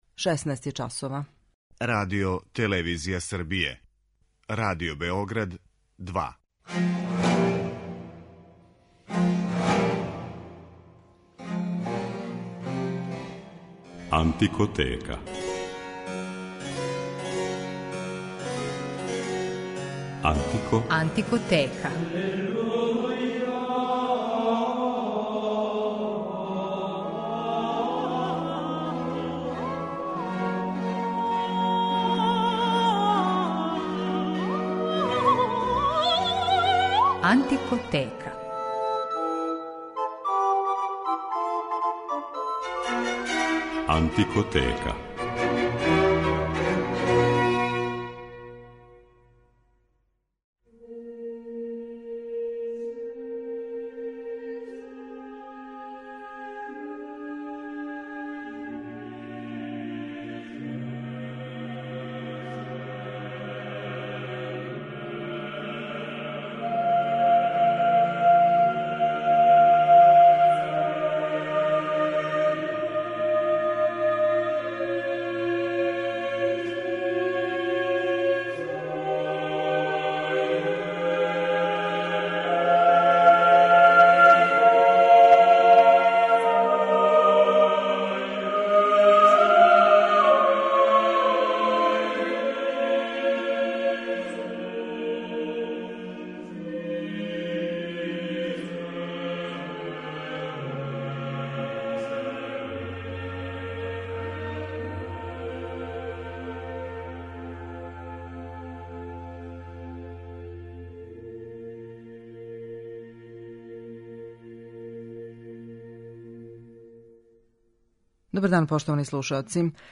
Данашња 'Антикотека' је посвећено једном од најбољих вокалних ансамбала на свету - The Sixteen - који од 1986. године има и оркестар.
води слушаоце у свет ране музике и прати делатност уметника специјализованих за ову област који свирају на инструментима из епохе или њиховим копијама.